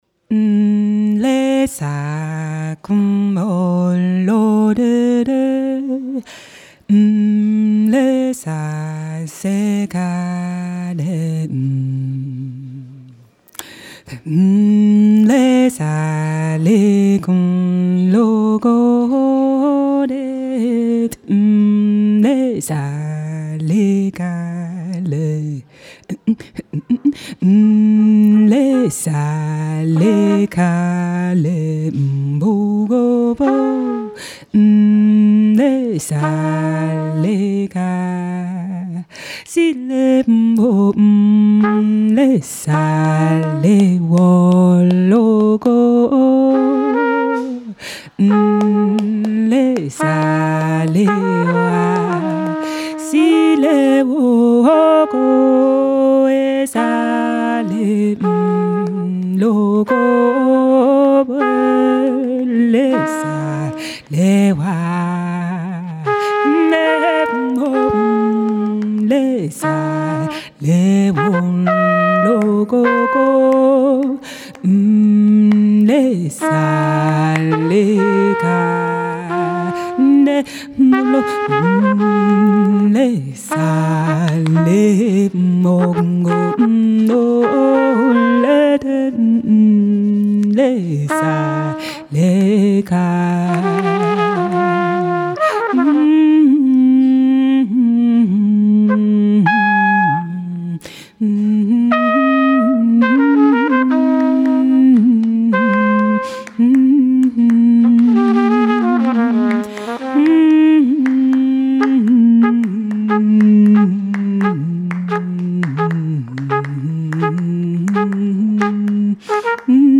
Albi Jazz Festival